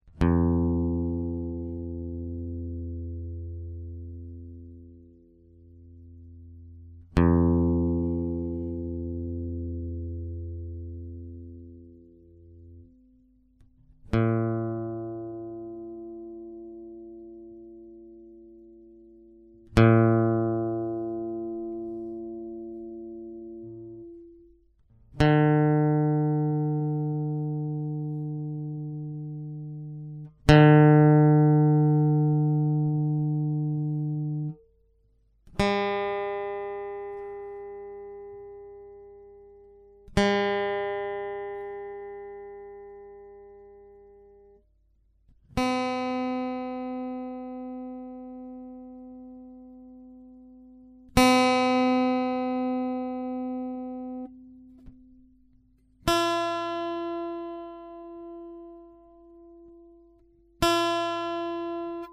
Songs in 1/4 up tuning:
Standard tuning but all strings are tuned 1/4 of a step up.
tuning_up.mp3